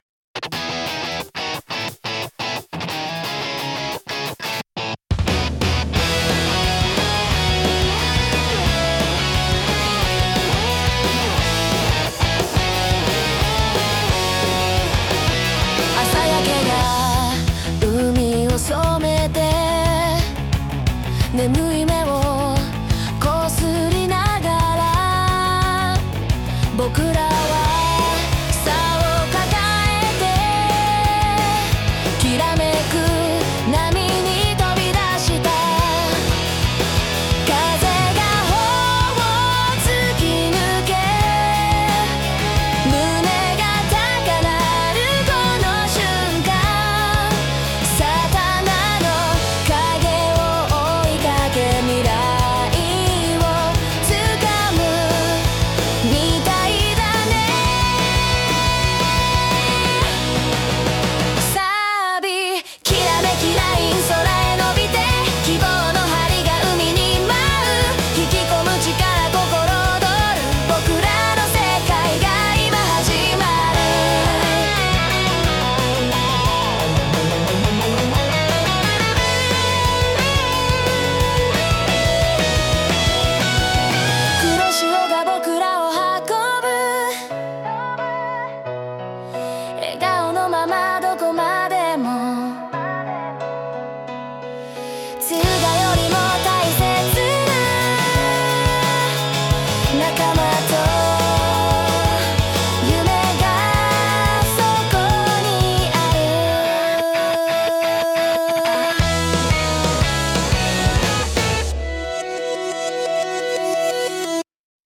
ロックバンドで元気に！